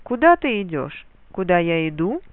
Мелодическая схема переспроса:
Кроме того, изменяется и мелодический рисунок: вместо нисходящего тона, как при инверсии, будет восходящий.